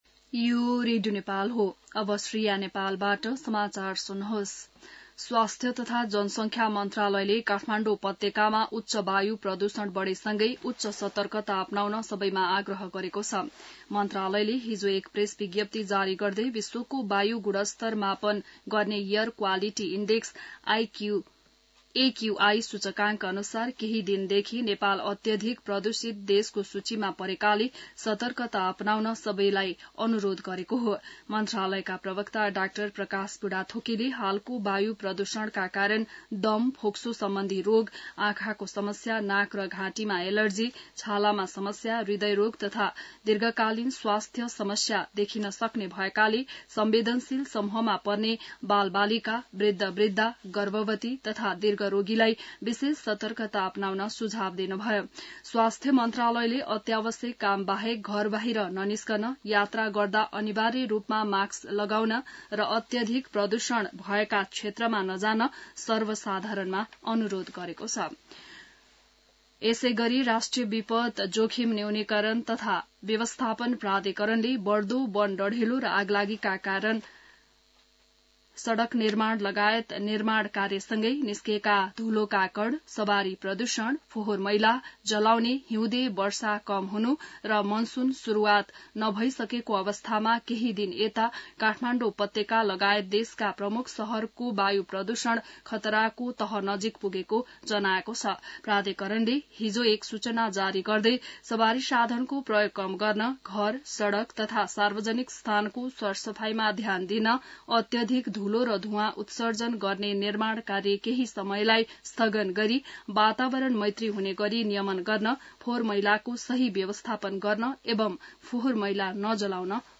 बिहान ६ बजेको नेपाली समाचार : २२ चैत , २०८१